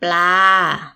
– bplaa